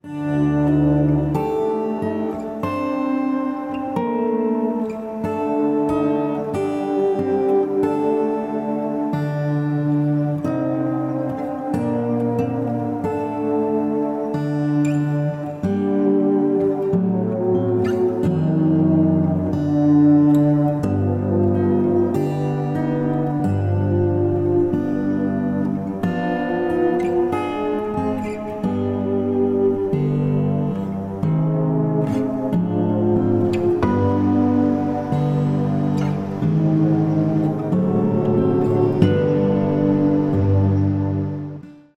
12-string LucyTuned guitar